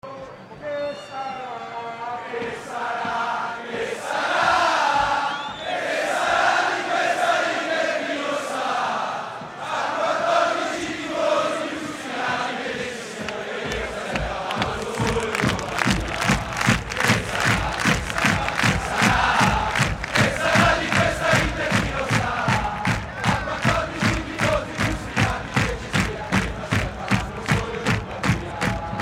Cori stadio - Juventus - Che sarà di questa inter.mp3